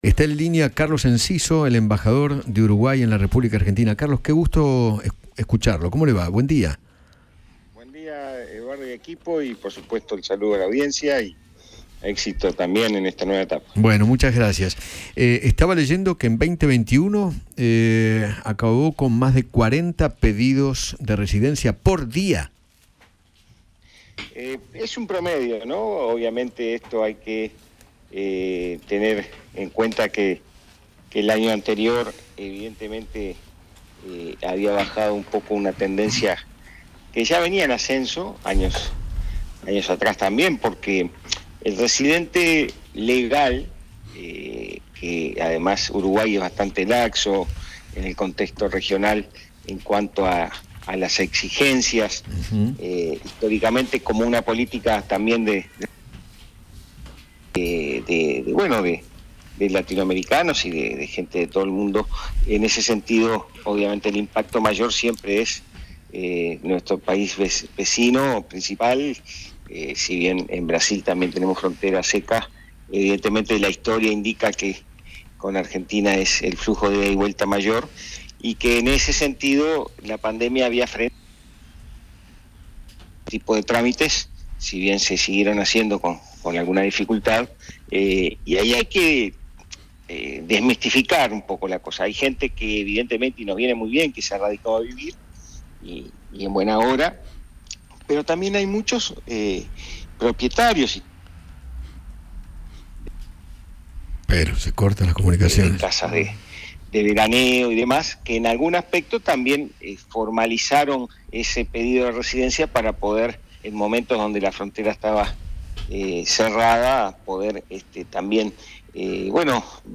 Carlos Enciso, embajador de Uruguay en Argentina, conversó con Eduardo Feinmann acerca del incremento de solicitudes de residencia de argentinos en el país vecino, tras registrarse una cifra récord de más de 12 mil pedidos durante 2021.